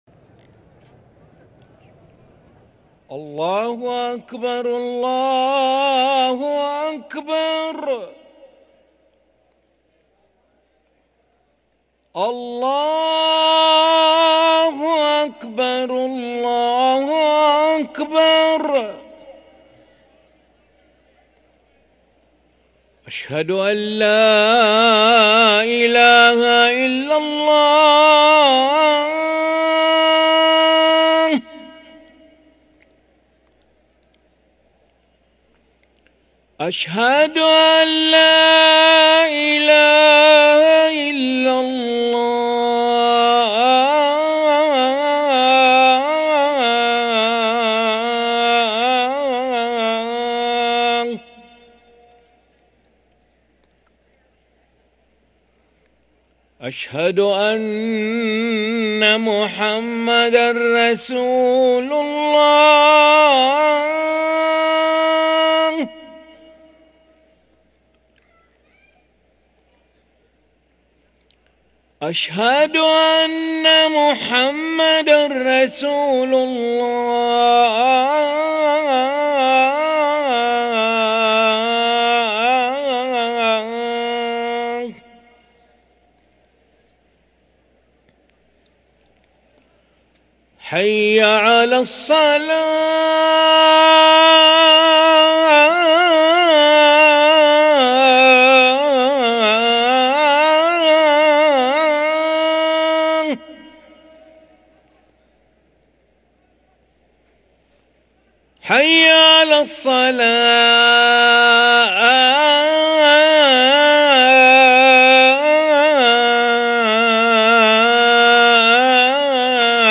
أذان العشاء للمؤذن علي ملا الأحد 28 رجب 1444هـ > ١٤٤٤ 🕋 > ركن الأذان 🕋 > المزيد - تلاوات الحرمين